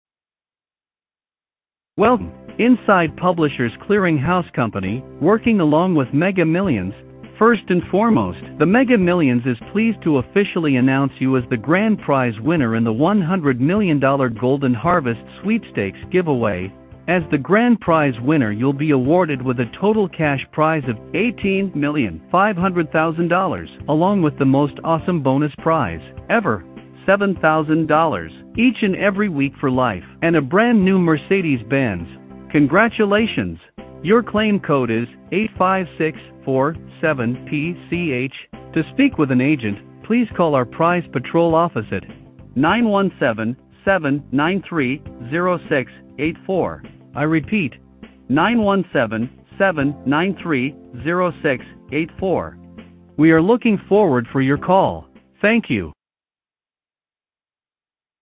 Robocall :arrow_down_small: